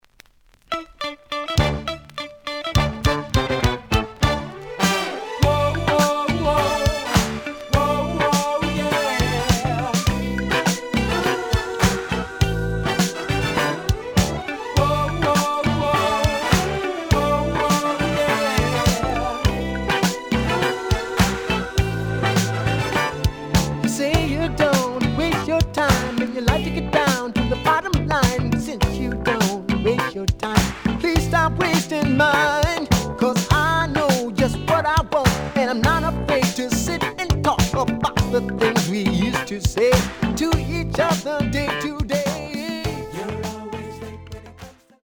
The audio sample is recorded from the actual item.
●Genre: Funk, 80's / 90's Funk
Slight edge warp.